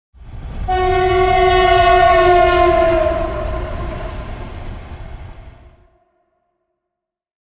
Train-passing-with-horn-sound-effect.mp3